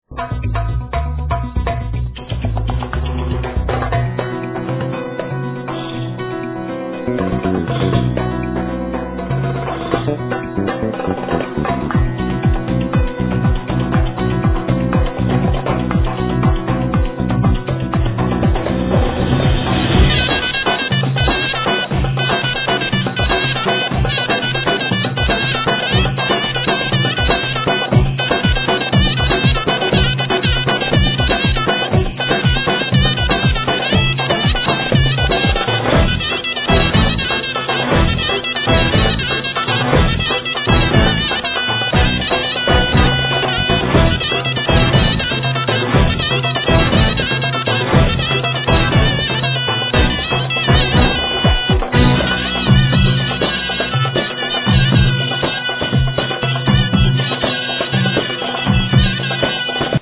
Voice, Vocals, Percussions
Kementse, Violin
Clarinet, Zournas, Ney
Tzouras, Baglamas, Keyboards